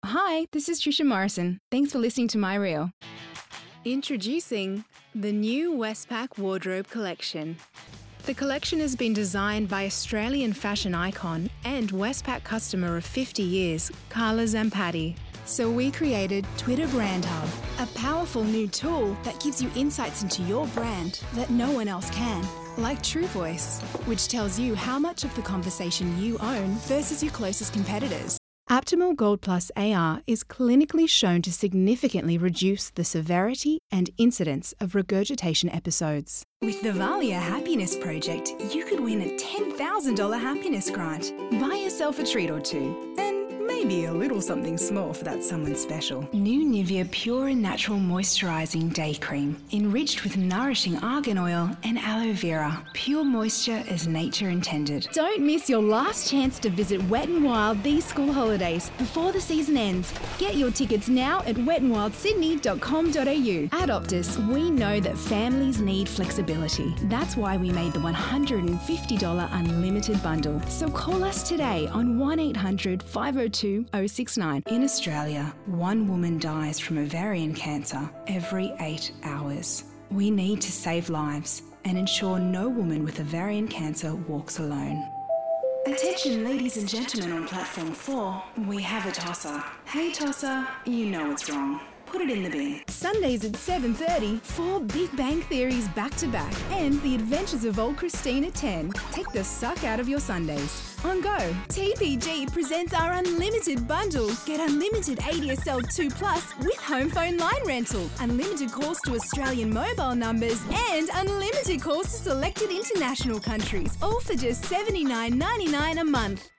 Voiceovers
Voice-reel-June-2019.wav